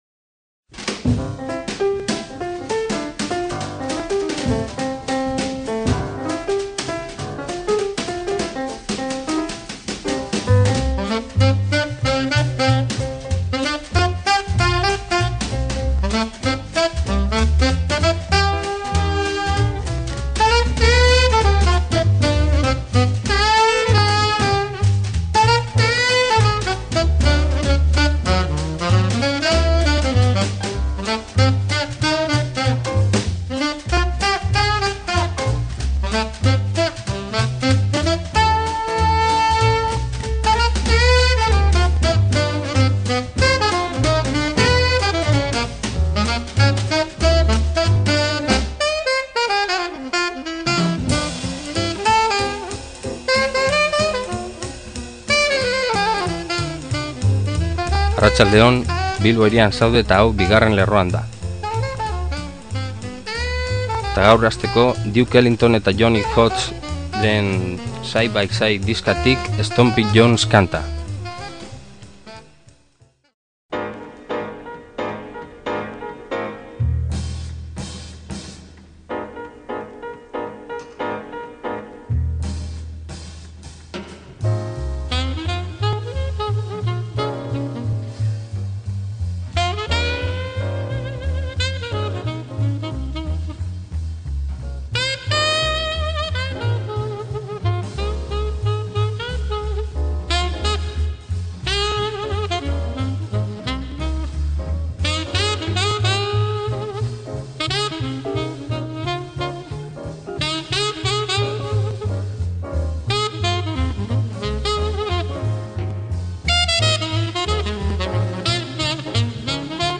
jazz
gitarrajole
eskaini zuen kontzertuaren zati bat entzutera
baxujolearen